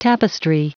Prononciation du mot tapestry en anglais (fichier audio)
Prononciation du mot : tapestry